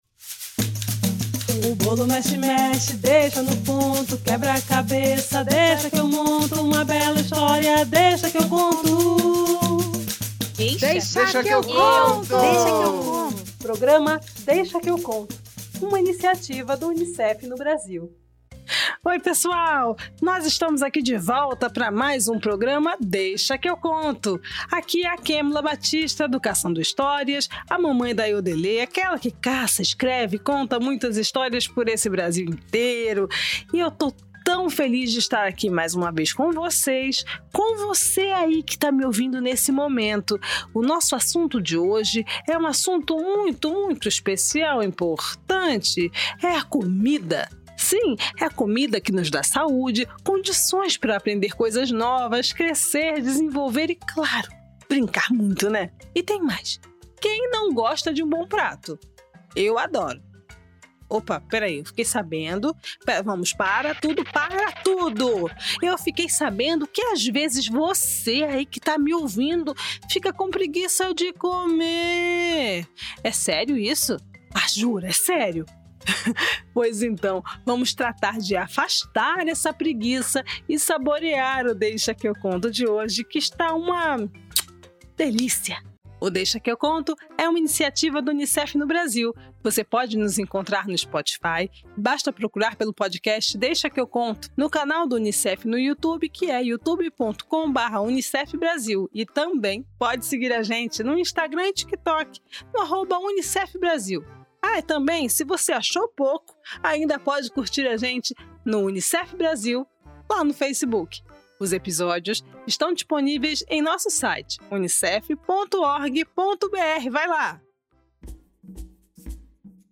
Neste episódio vou contar uma história da tradição Iorubá que nos revela como Xangô ensinou os homens e as mulheres a usar o fogo para cozinhar. Comidas faladeiras que adoram contar histórias ganham vida através das músicas com “Iyá Iyá” e os “Erês”.